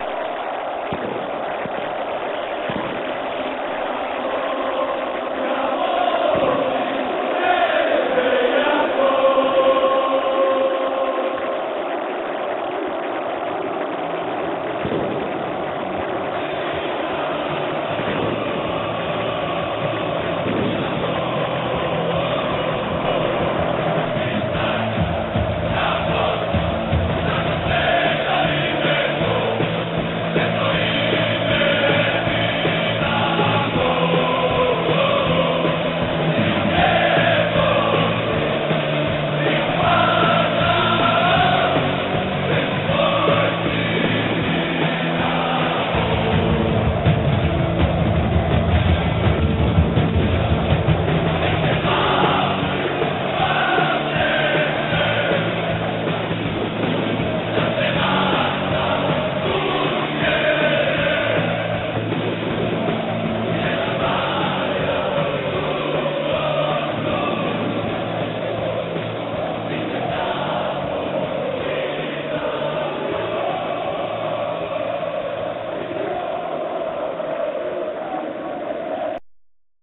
Snimke od kojih je nastao film napravljene su digitalnim fotoaparatom, za vrijeme utakmica. Kvaliteta tih snimaka je loša zbog specifičnih uvjeta, te je to dodatno umanjilo kvalitetu samog filma.